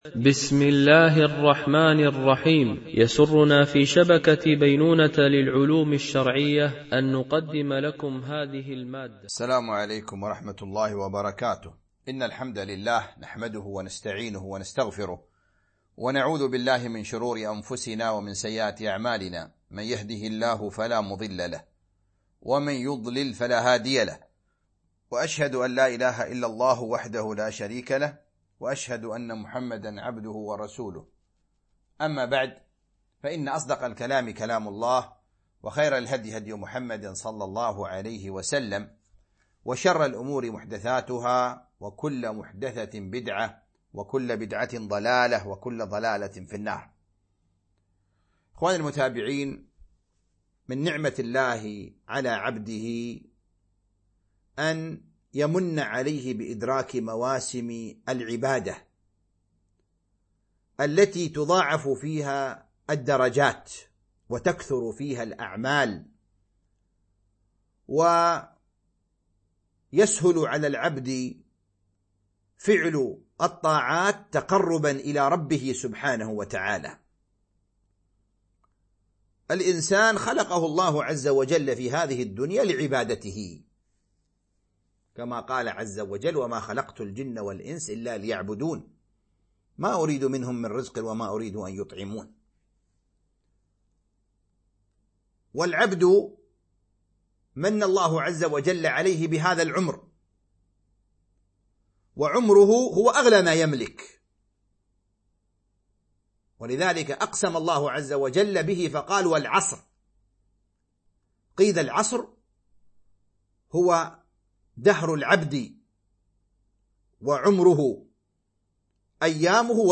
القسم: التفسير